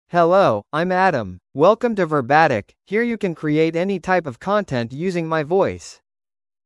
Adam — Male English (United States) AI Voice | TTS, Voice Cloning & Video | Verbatik AI
AdamMale English AI voice
Adam is a male AI voice for English (United States).
Voice sample
Listen to Adam's male English voice.
Adam delivers clear pronunciation with authentic United States English intonation, making your content sound professionally produced.